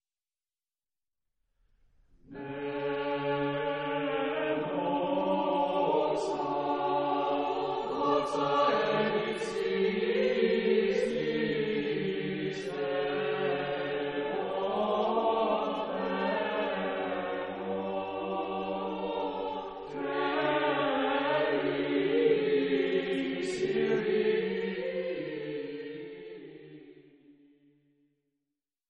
Christmas Music from the Byzantine Tradition